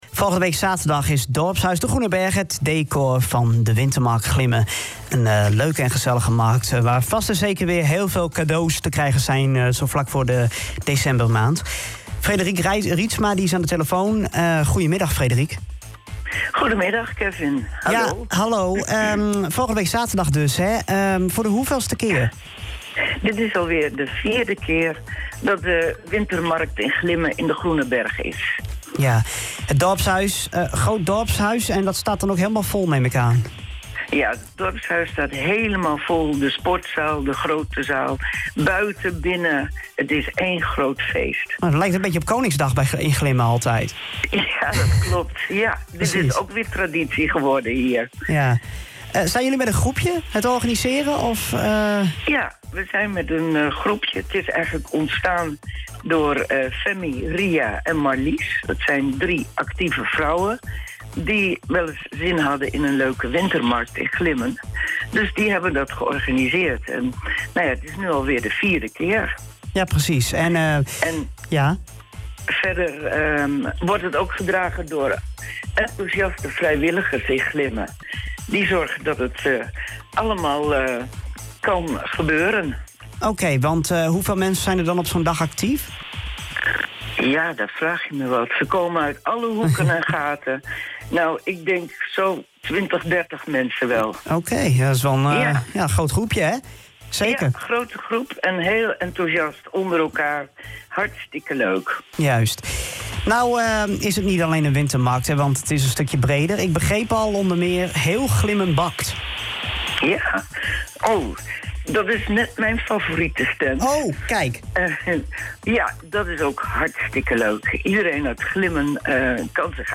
Luister het hele radio-interview uit Haren Doet hier terug: